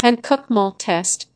Linear-Spectrogram은 Griffin-Lim Algorithm이라는 음성 재구성 알고리즘을 통해서 음성으로 변환
1. 영어로 한국말을 적은 경우